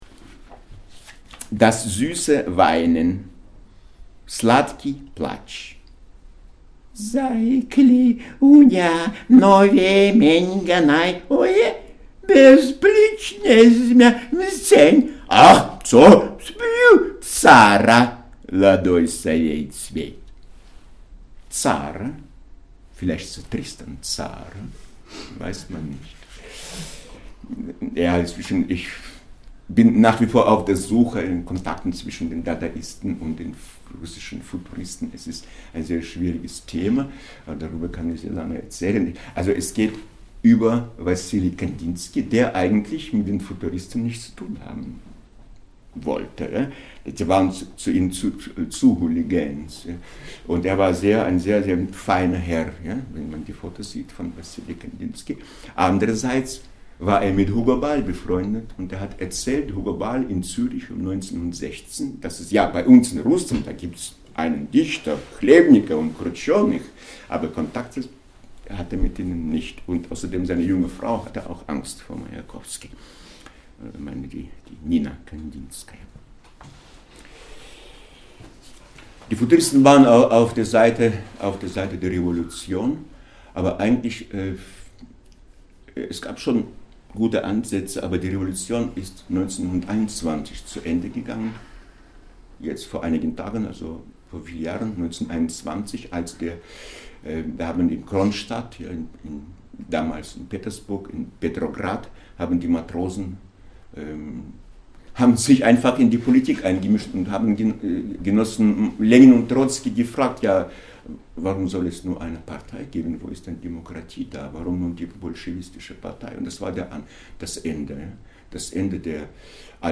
Auch wer weder Russisch noch Sa-umnisch beherrscht, kann deutlich hören, wie der rauhe „Tsara“ in das Süßholzgeraspel der Poesie einbricht (bei 0:16).